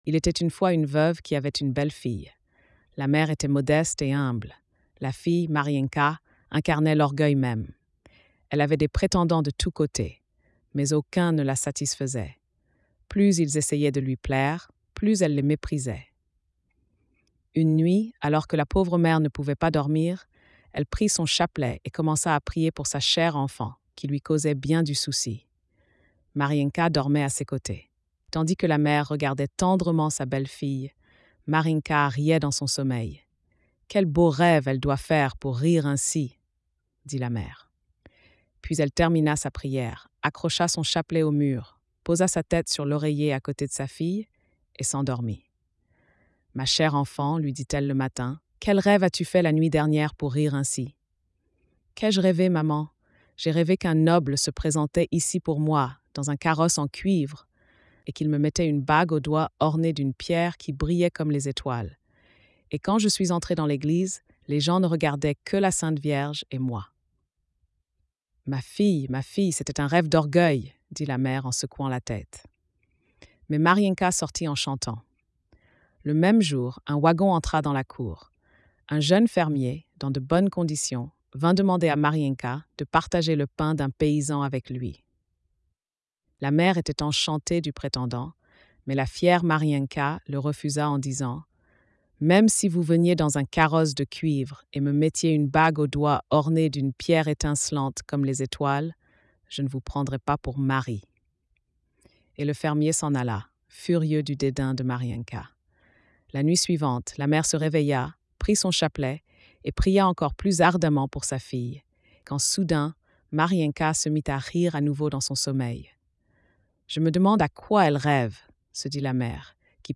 🎧 Lecture audio générée par IA
Conte : Le Pain d’Or